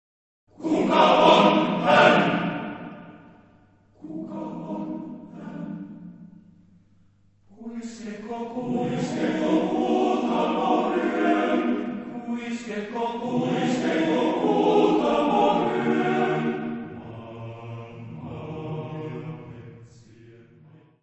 : stereo; 12 cm
Music Category/Genre:  Classical Music